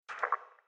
splat_alt.mp3